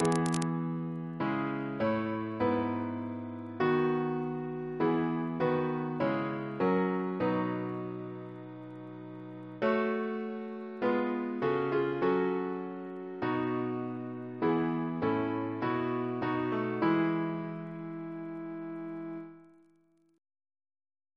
Double chant in E Composer: Edward Blake (1708-1765) Reference psalters: ACP: 283